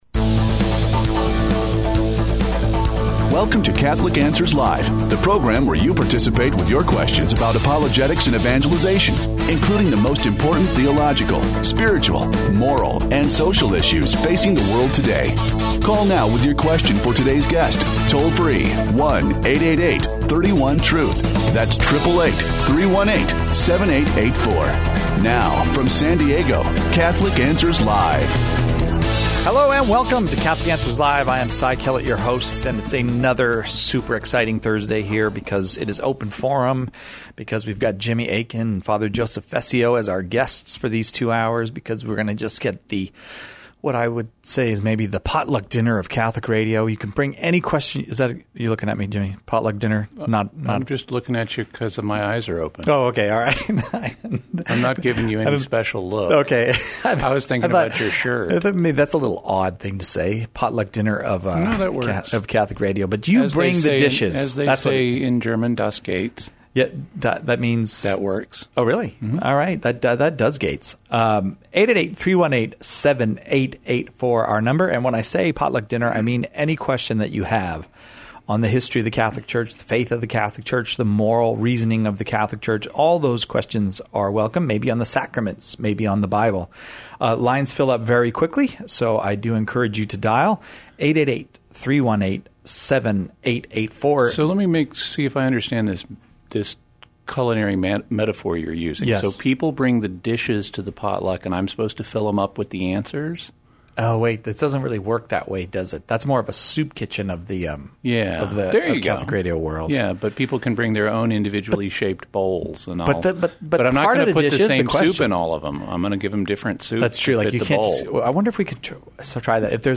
The callers choose the topics during Open Forum, with questions on every aspect of Catholic life and faith, the moral life, and even philosophical topics.